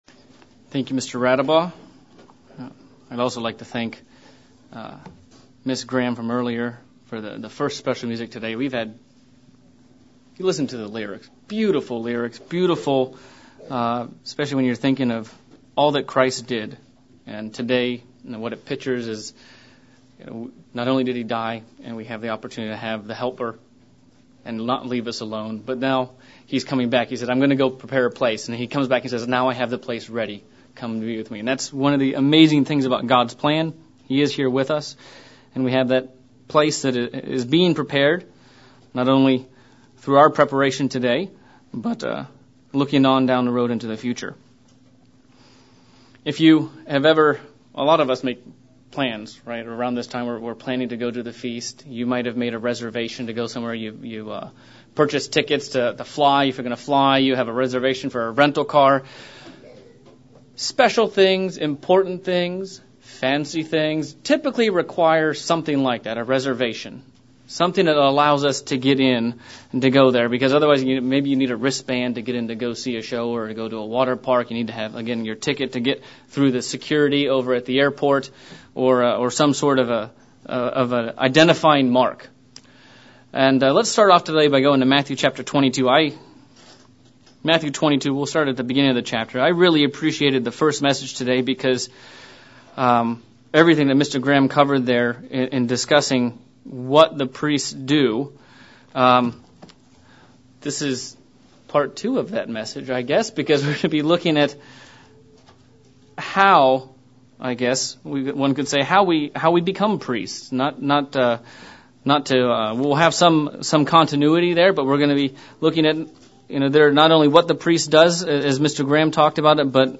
Trumpets PM sermon looking at the duties and responsibilties of a priest and are we prepared to fulfill these duties